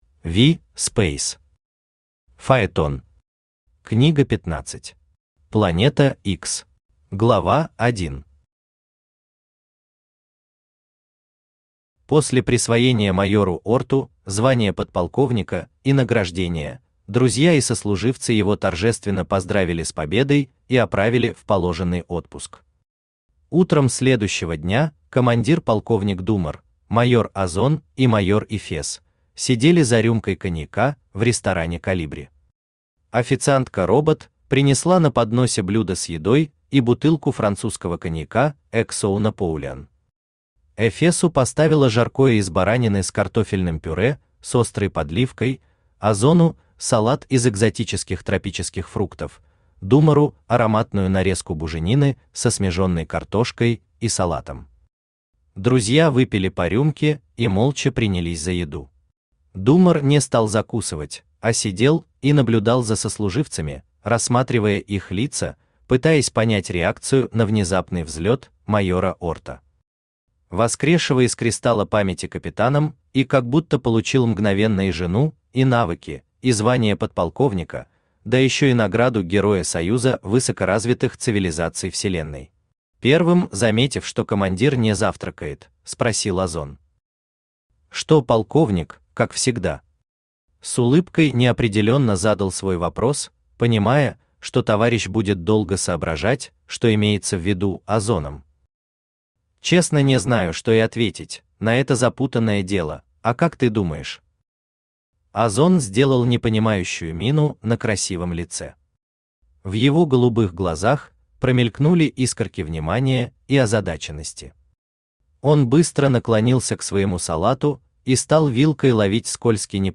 Аудиокнига Фаетон. Книга 15. Планета Икс | Библиотека аудиокниг
Планета Икс Автор V. Speys Читает аудиокнигу Авточтец ЛитРес.